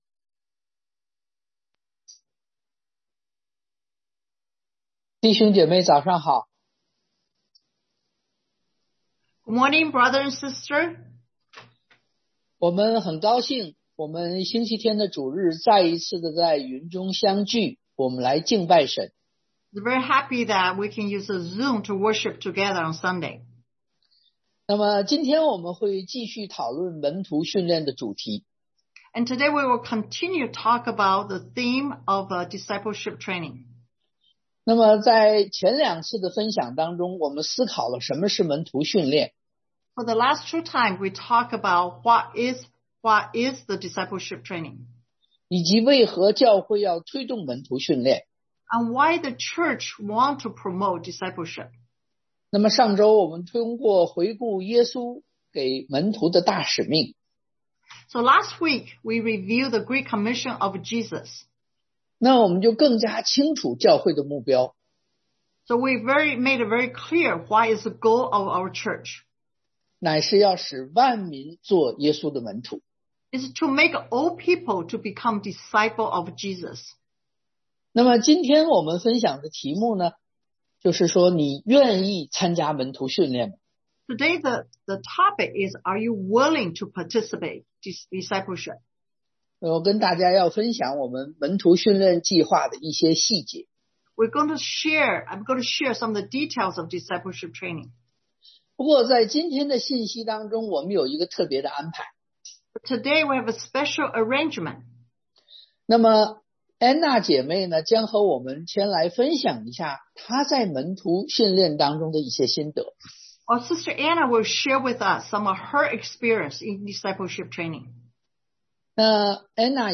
2Tim 2:2 Service Type: Sunday AM Are You Willing to Participate?